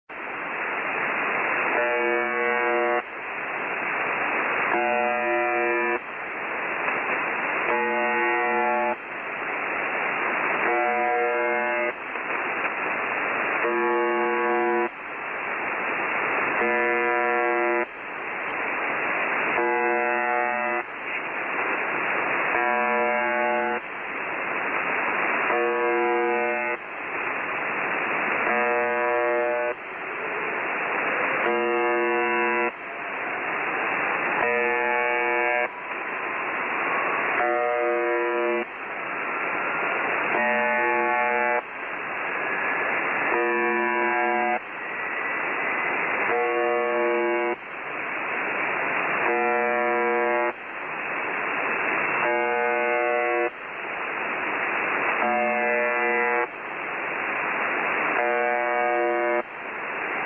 The Buzzer, widely known as UVB-76, was first spotted in the late 70’s and is known for its constant buzzing on 4625 kHz.
However, it’s clear the buzzing is used as a channel marker and to keep the frequency clear so nobody will transmit over the sound.
Now the buzzing tone lasts 1.25 seconds, with a 1.85 second pause.
The-Buzzer-UVB-76.ogg